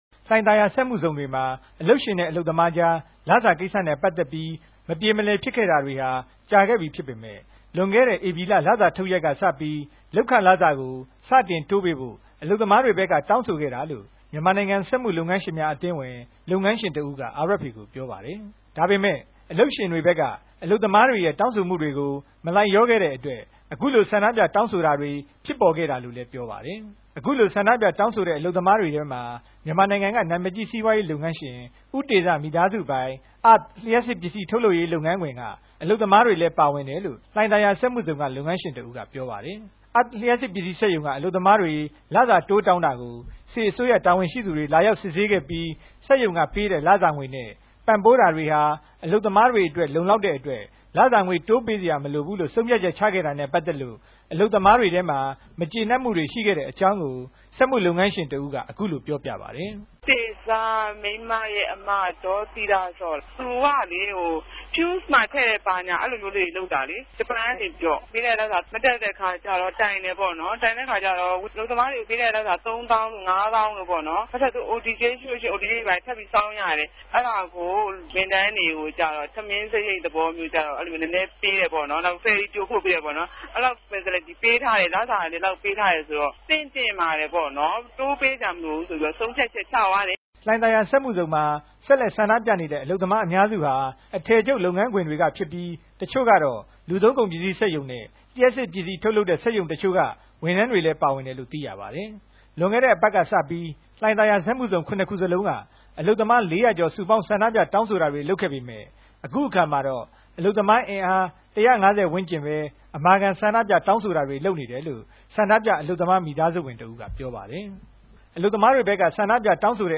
RFAသတင်းထောက်